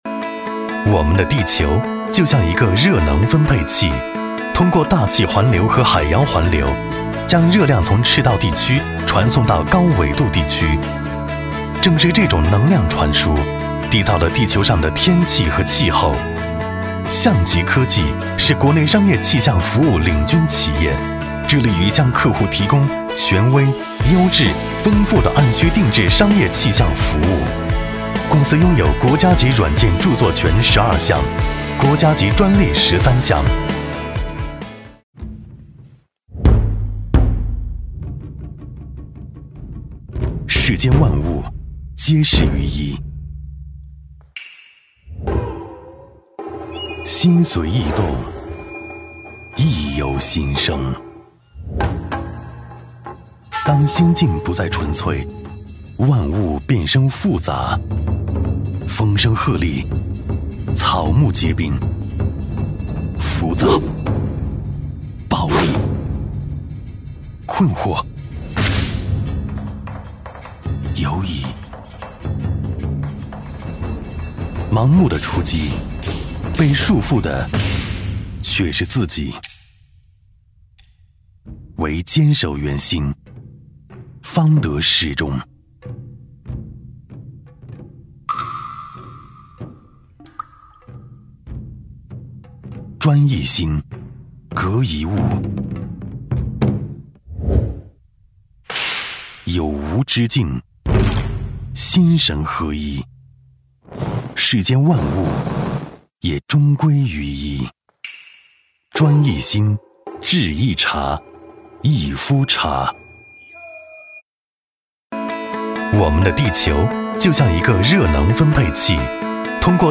职业配音员全职配音员温柔磁性
• 男S356 国语 男声 广告一夫茶 激情激昂|大气浑厚磁性|科技感|积极向上